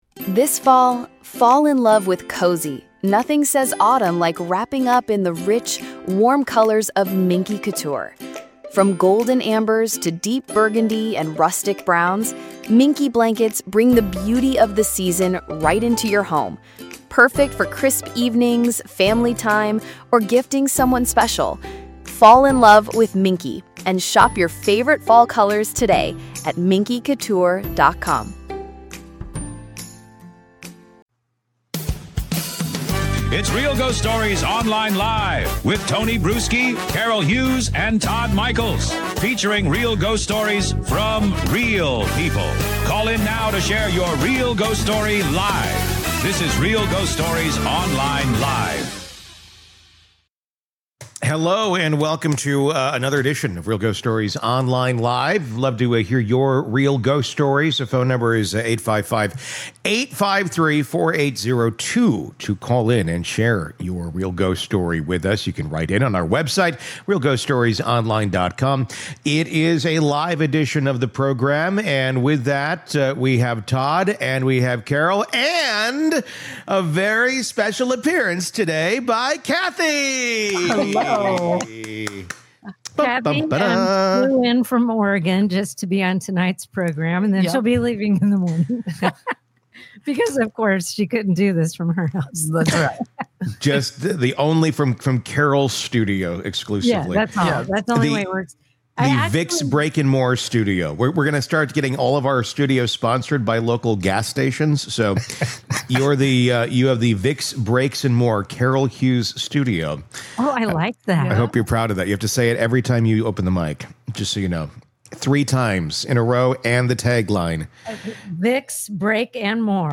They debate how much haunted objects can influence a house, and how sometimes the scariest part of a haunting is the way ordinary things — like photos or toys — suddenly feel wrong. This segment balances unsettling details with the hosts’ banter, bringing you that perfect mix of chills and laughs.